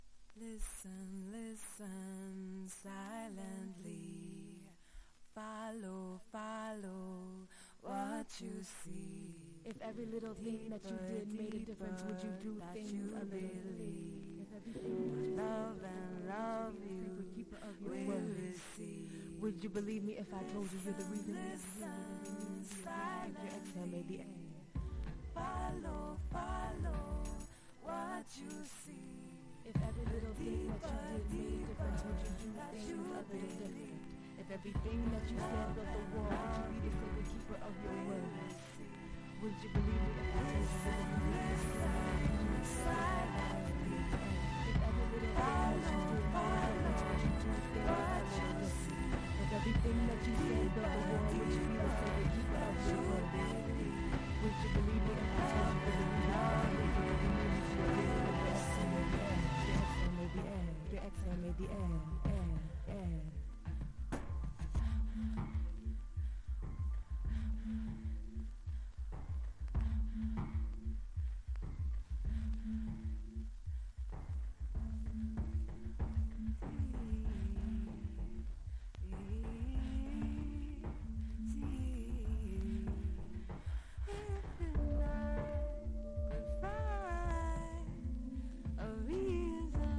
HIPHOP# UNDERGROUND